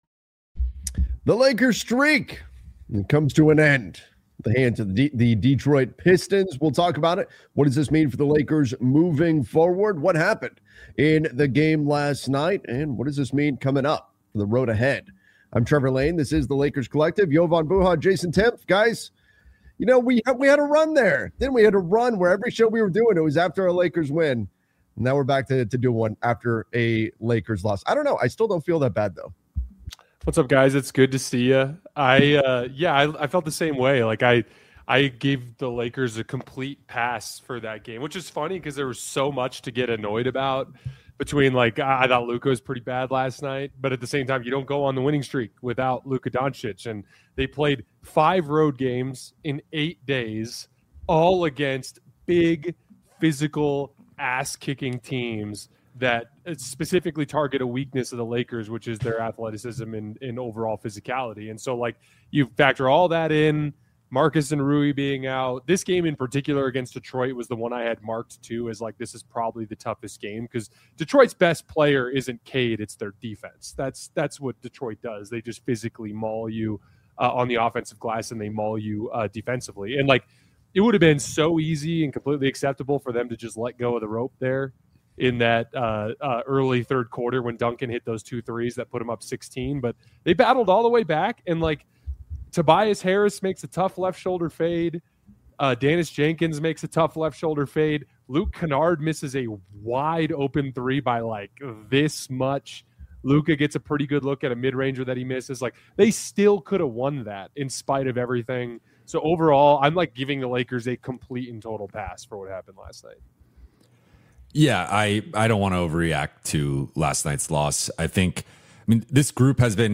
Welcome to the Lakers Collective — a weekly Los Angeles Lakers roundtable